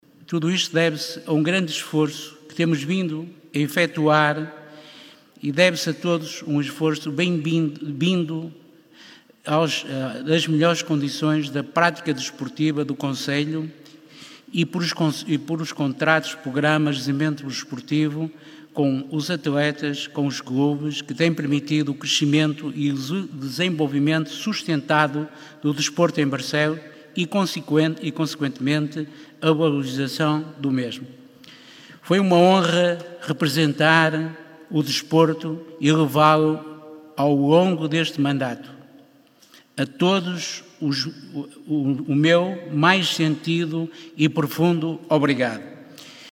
A II Gala do Desporto de Barcelos decorre neste momento, no Pavilhão Municipal de Barcelos, e homenageia mais de uma centena de personalidades, atletas, associações e clubes, que se destacaram nas mais diversas modalidades, nas épocas desportivas de 2018/19 e 2019/20.
No discurso de abertura, Francisco Rocha, vereador do Município com pelouro do desporto, valorizou e agradeceu o trabalho feito nos últimos 4 anos.